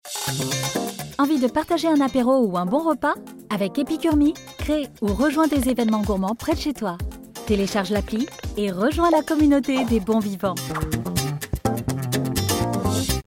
Voix off
30 - 70 ans - Mezzo-soprano